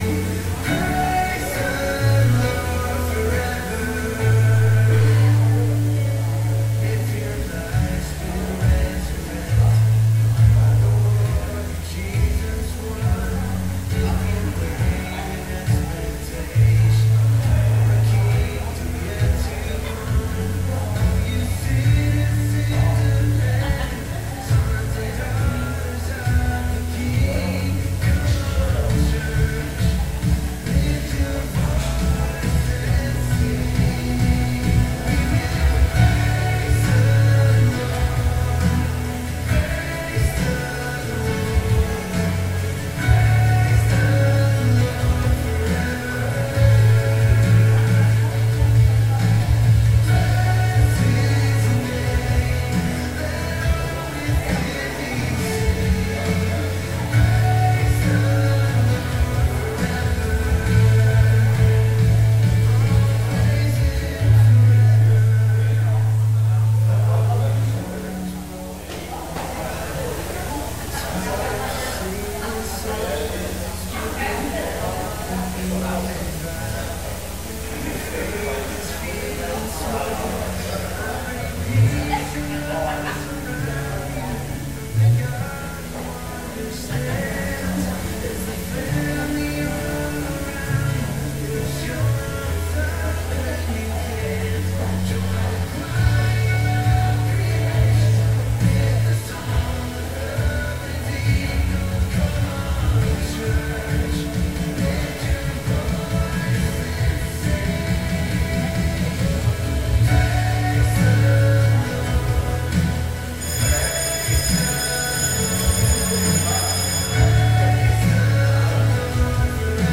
Sunday Morning Teaching